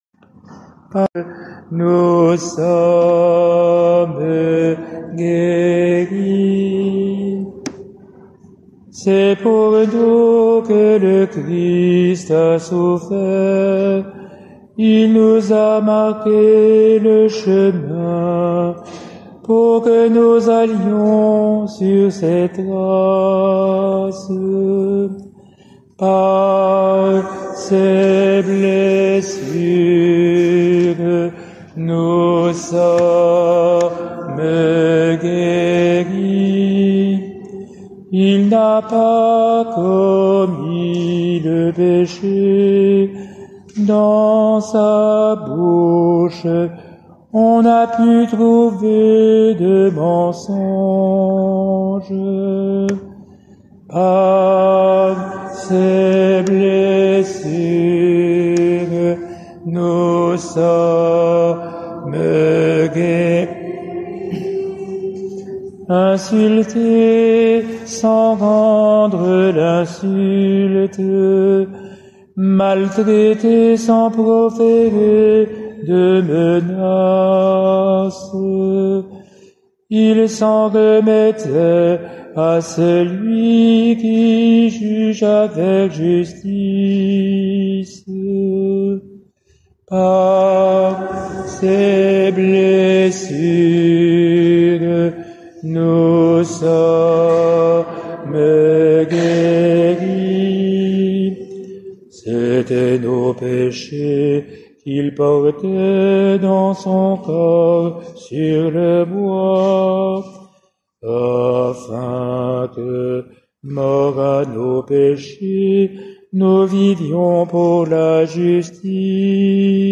Enseignement sur le Triduum pascal – Saint-Louis d'Antin